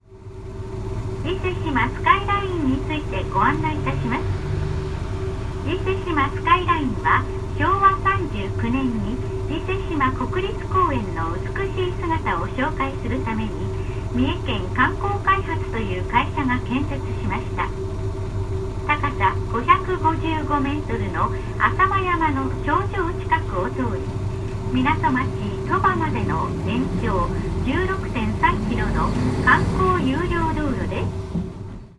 ほぼ全線にわたり、車内では自動放送による沿線案内が流れています。
50系統 車内放送の一部(32秒・631KB)